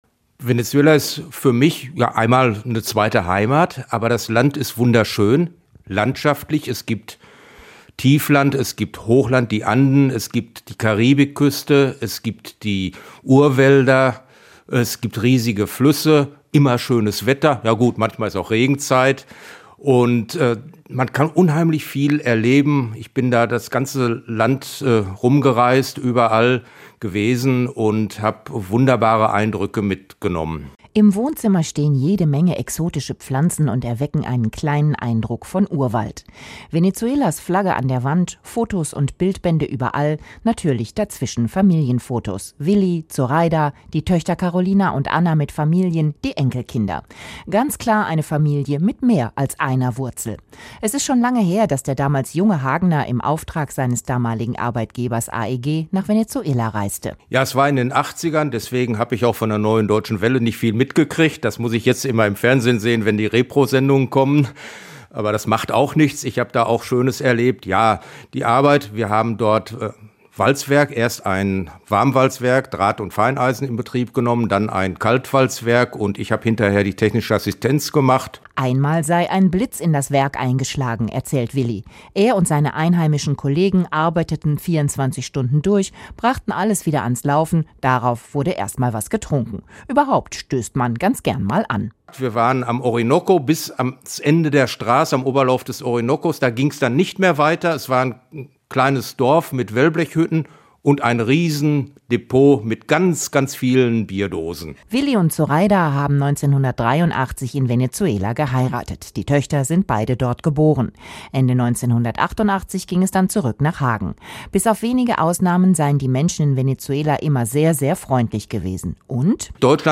unterhalten